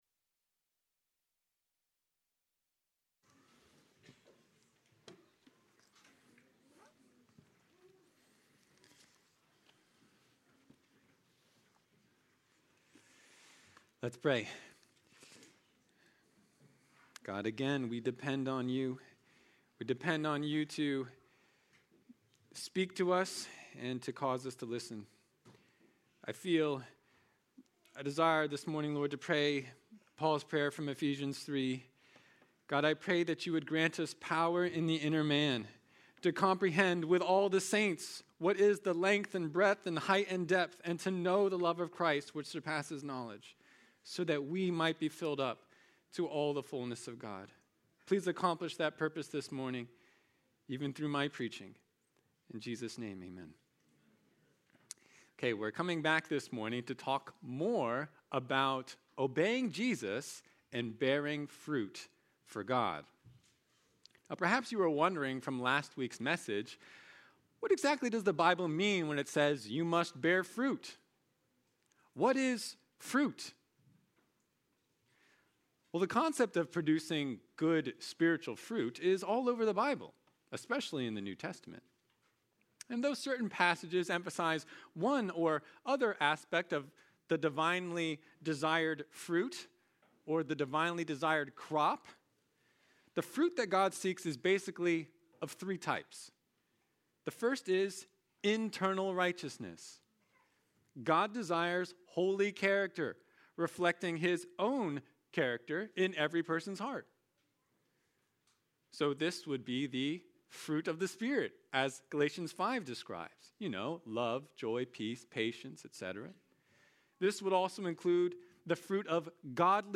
February 8, 2026 Sermon Are You a Friend of Jesus?